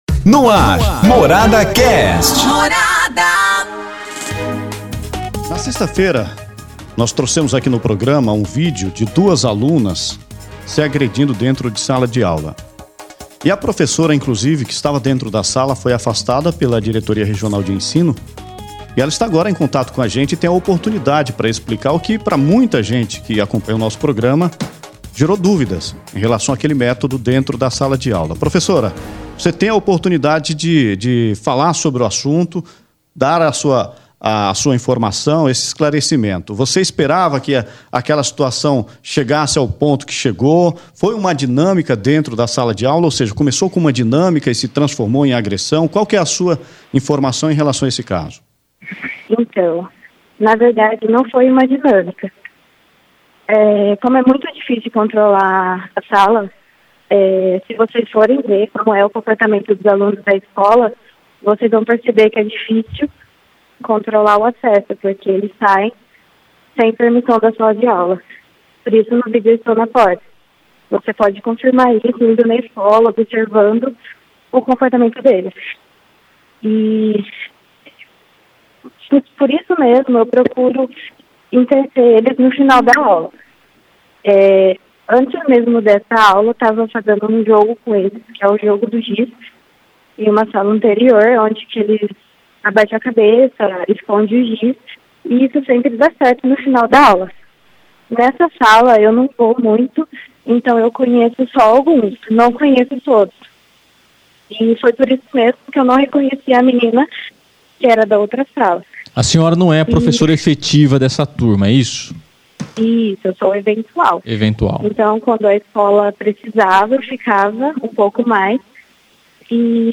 Nossos jornalistas discutem como atividade em sala de aula termina com briga entre alunas. Professora esclarece o caso pela primeira vez.